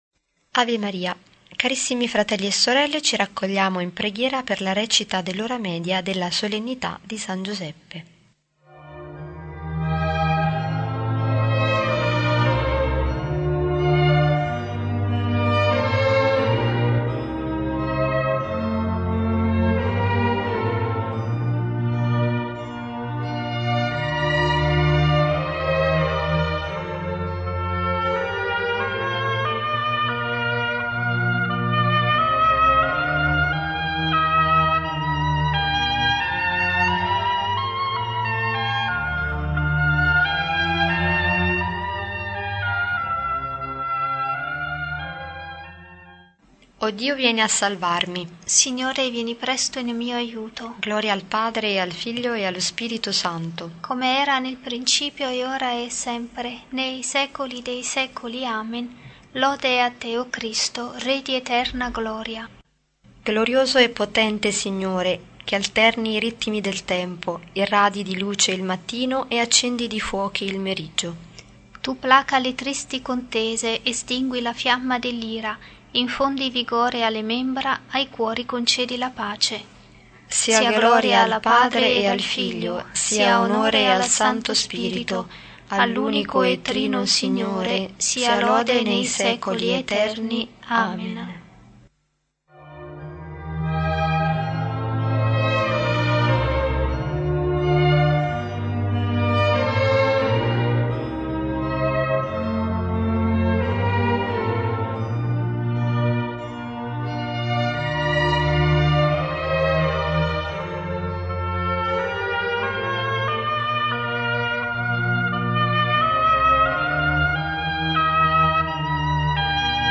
03-19-O.M.Sol_.S.Giuseppe-T.Q.-salmodia-complementare.mp3